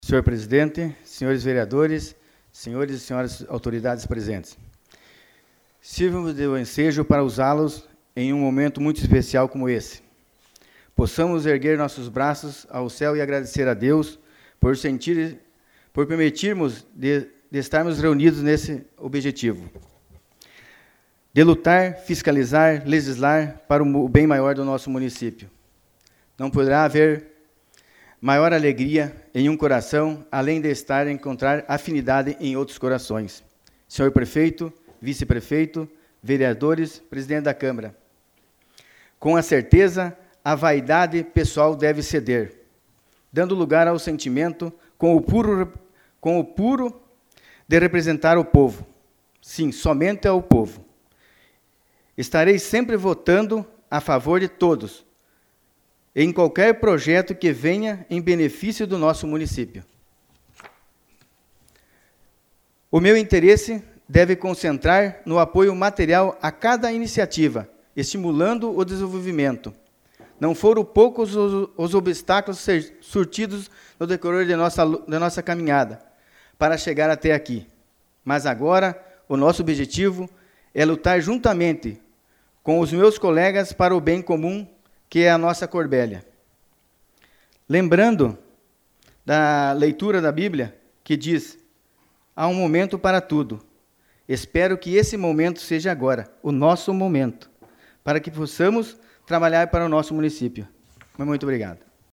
Áudio da Tribuna: Vereador Volmir Gronenfeld Reis - Nene na 1ª Sessão Ordinária